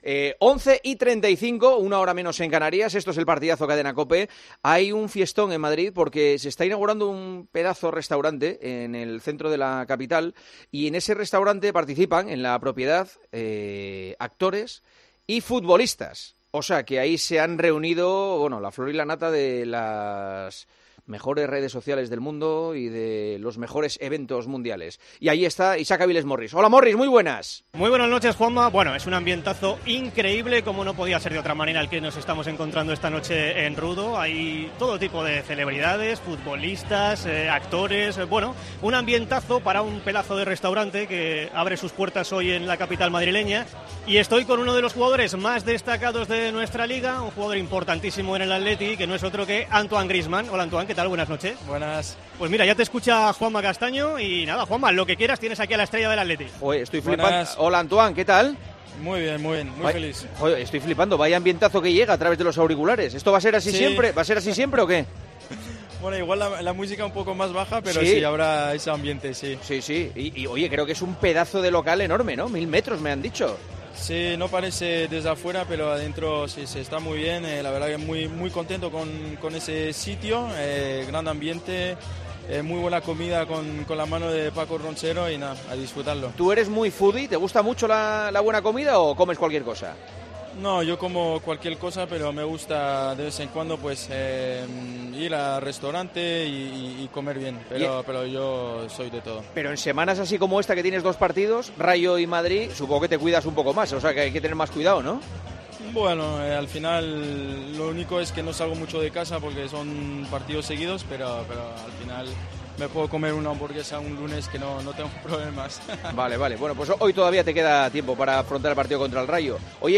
Juanma Castaño entrevistó al delantero del Atlético de Madrid en la semana en la que se enfrentará ante el Real Madrid por tercera vez en tres semanas.